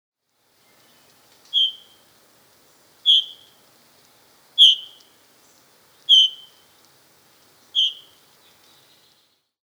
Звуки дрозда
Звук высокого птичьего крика маленького дрозда